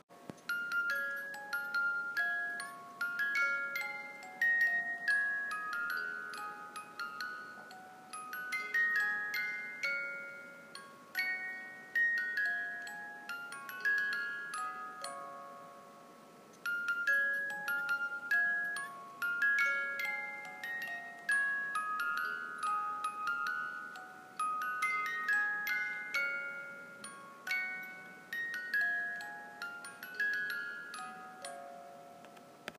Movimenti musicale meccanico 18 lamelle.